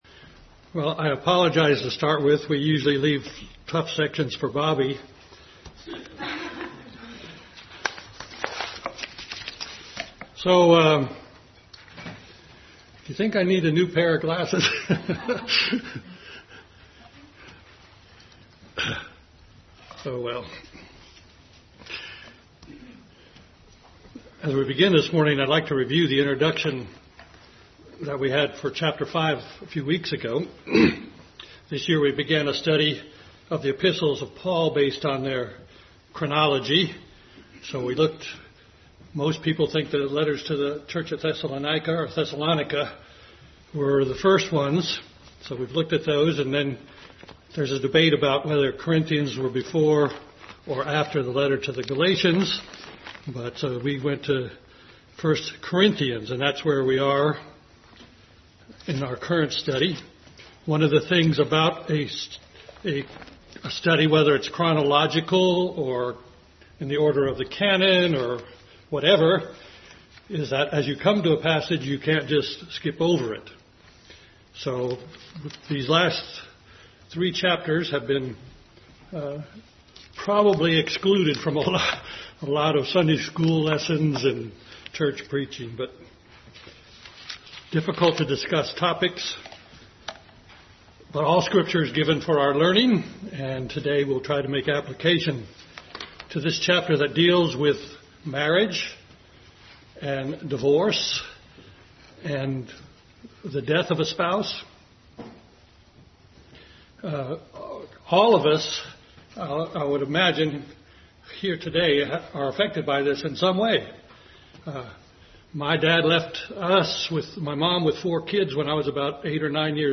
Bible Text: 1 Corinthians 7:1-40 | Adult Sunday School class continued study of 1 Corinthians.